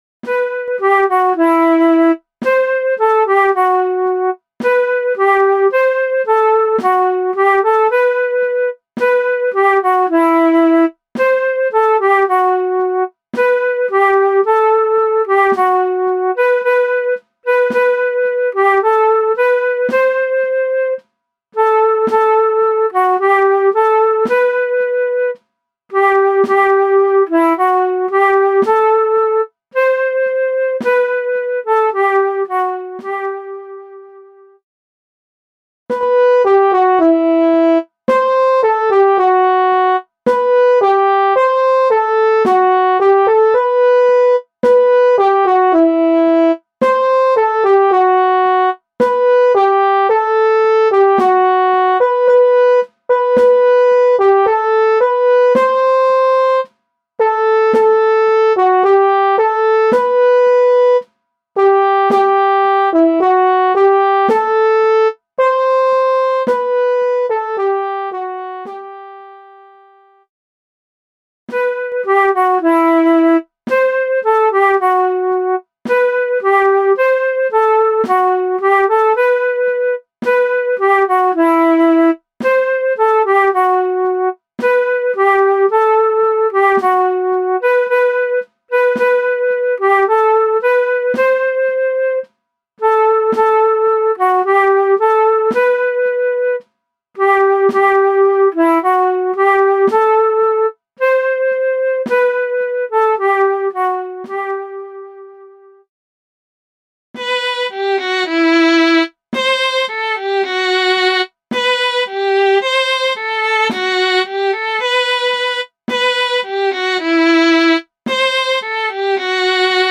MIDI - 1-stimmig